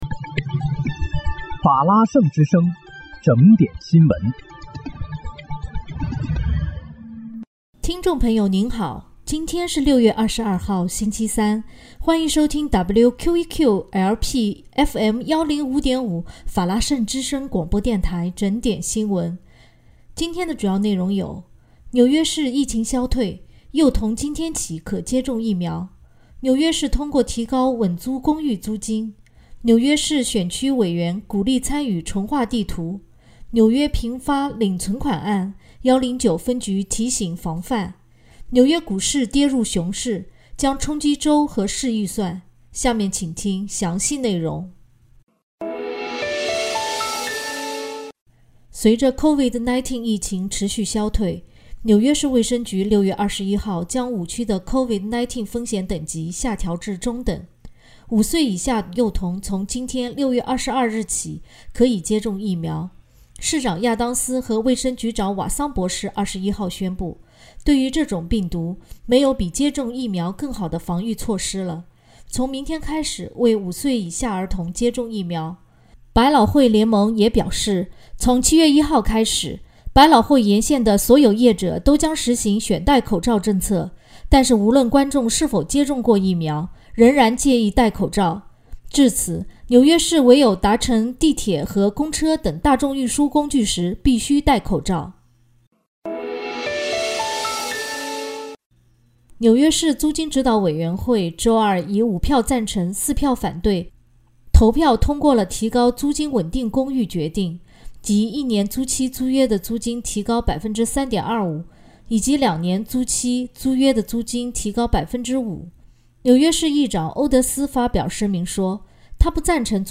6月22日（星期三）纽约整点新闻